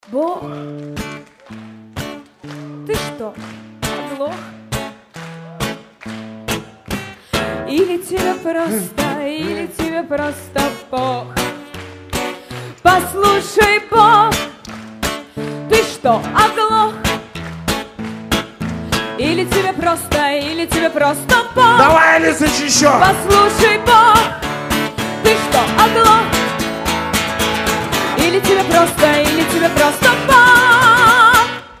• Качество: 320, Stereo
женский вокал
ироничные
смешные
акустика